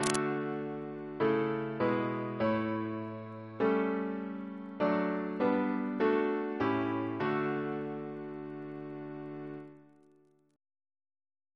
Single chant in D Composer: Thomas W. Hanforth (1867-1948) Reference psalters: ACB: 25; H1940: 605